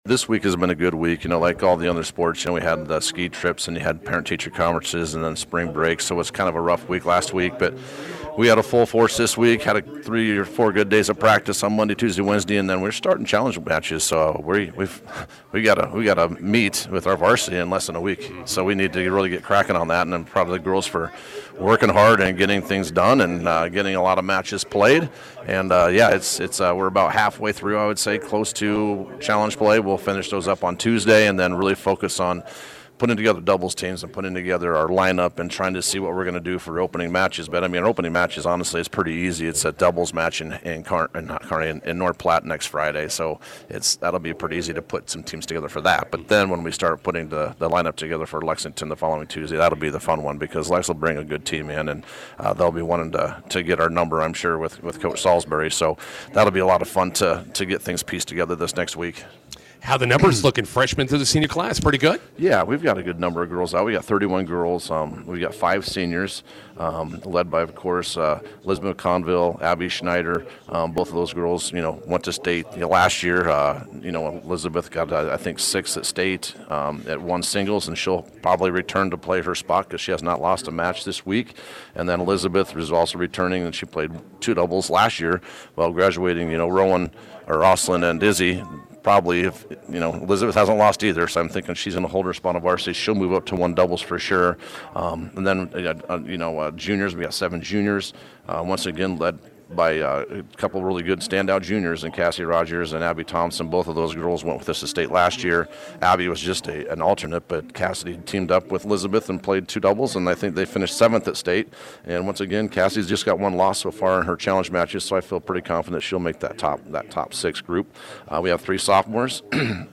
INTERVIEW: Bison girls tennis open with doubles tournament on Friday.